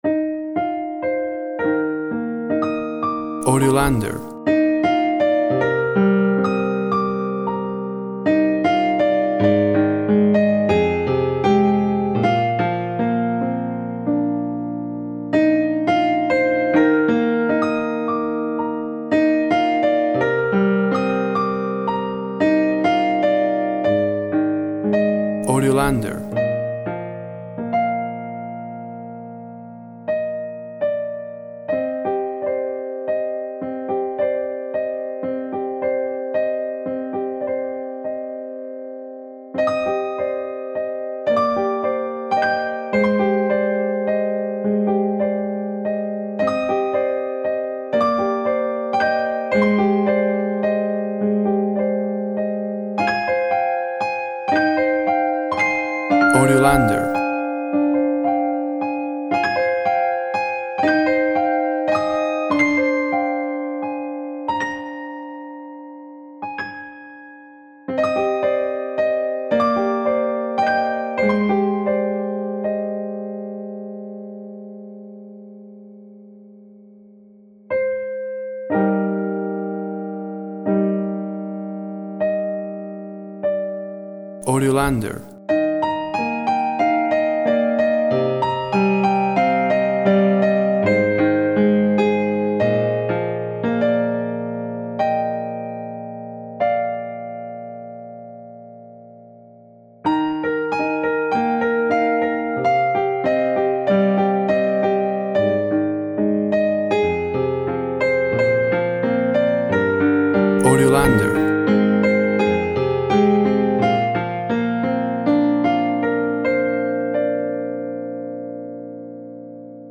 A piano plays a song of longing, based on a birdsong.
Tempo (BPM) 60/48